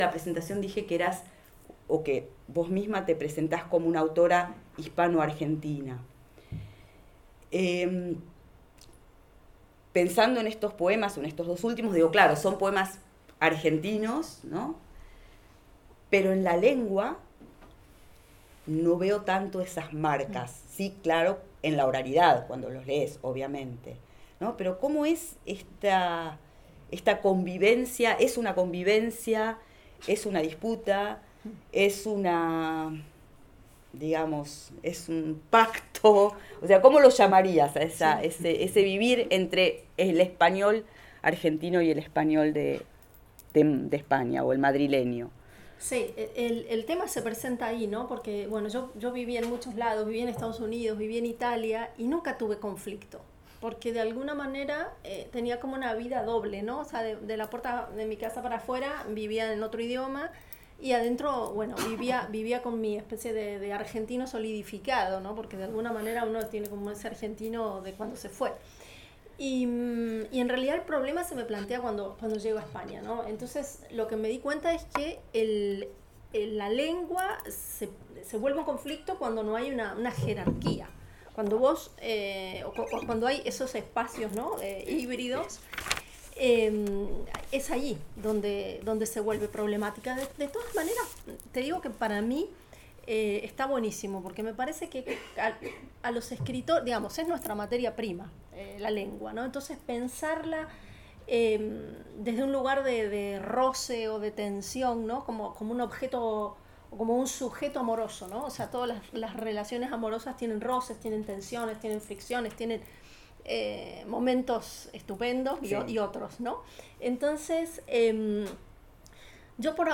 Encuentros literarios en Siegen
Entrevista